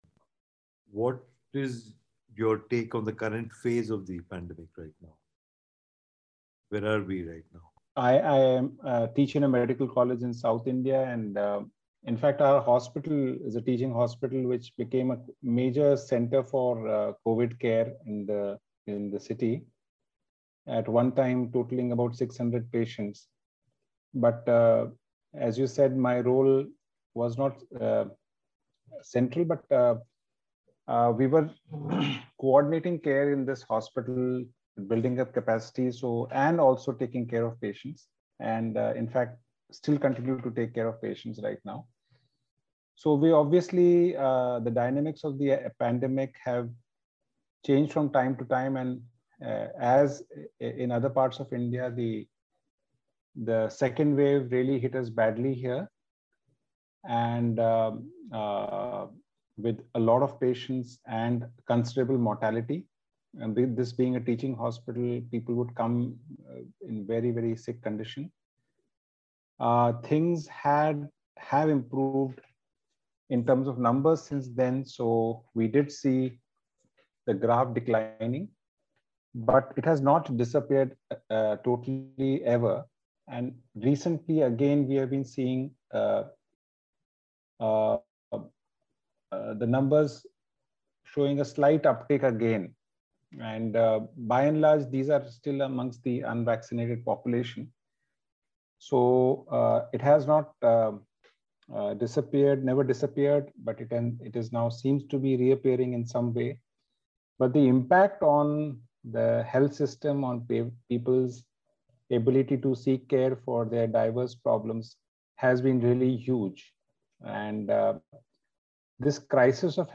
In this series of interviews Covid Response Watch brings you the voices of doctors, health workers, social activists and others, working ats the grassroots, speaking about the multiple impacts of the Covid pandemic on the lives of ordinary Indian citizens.